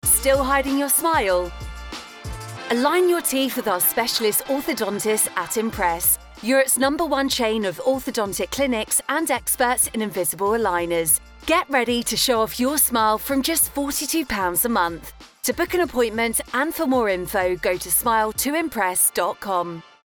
Sennheiser MK4
Jovem adulto
Mezzo-soprano